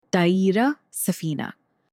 تلفظ “طاٸره” (taa’irah) با صدای “ط” که کمی متفاوت از “ت” فارسی است، شروع می‌شود و با “ة” به پایان می‌رسد که در مکالمه معمولاً به صورت “ه” ساکن تلفظ می‌شود.
airplane-in-arabic.mp3